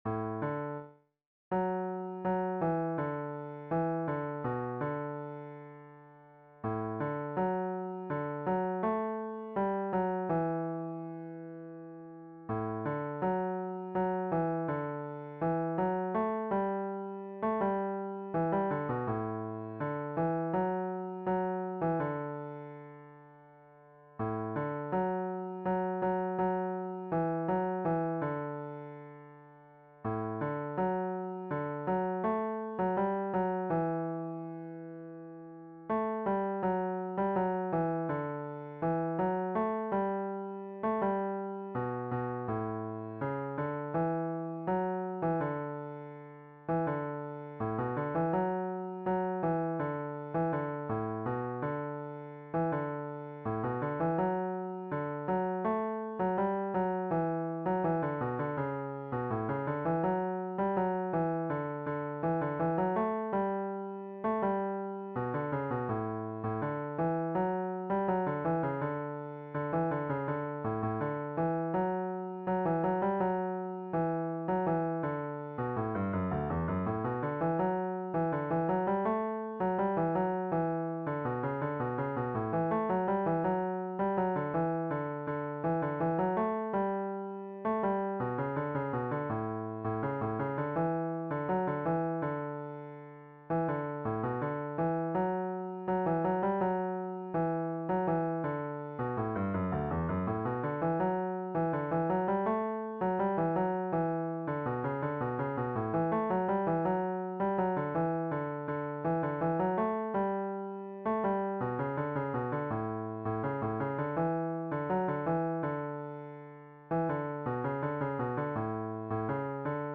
DIGITAL SHEET MUSIC - CELLO SOLO
Cello Melody Only, Traditional Fiddle Tune